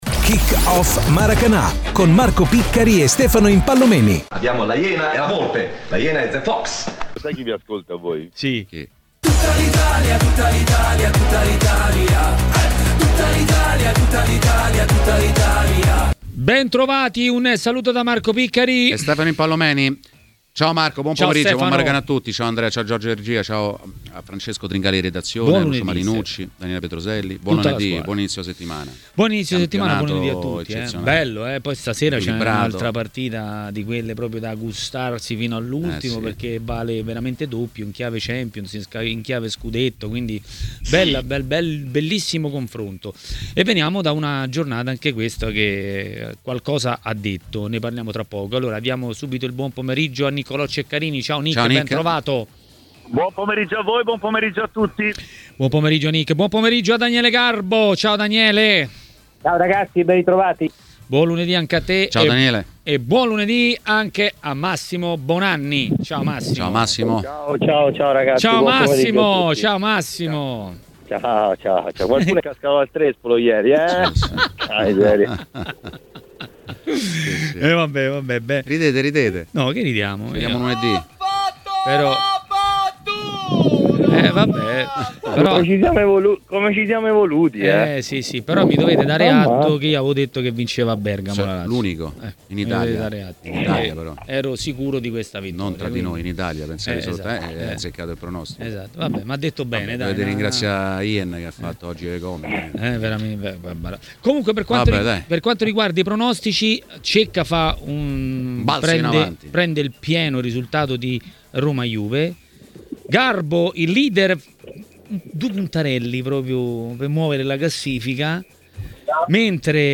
A commentare il turno di Serie A a Maracanà, nel pomeriggio di TMW Radio